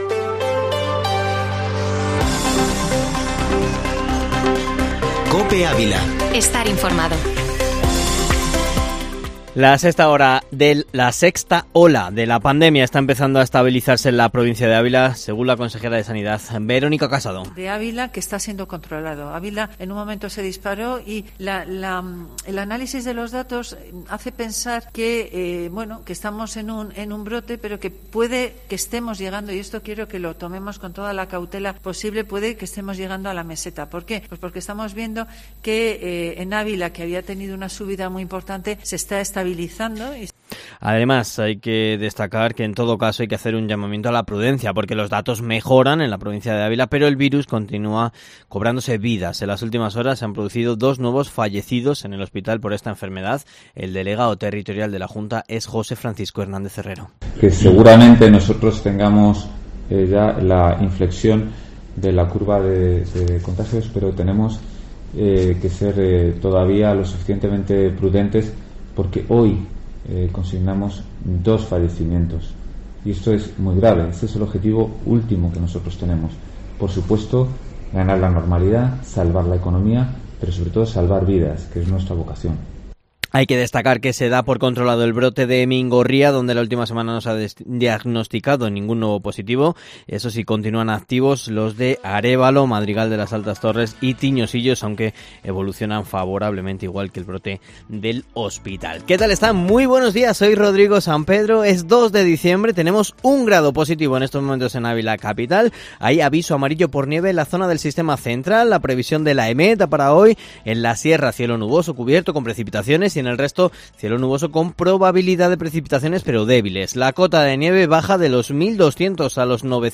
Informativo Matinal Herrera en COPE Ávila -2-dic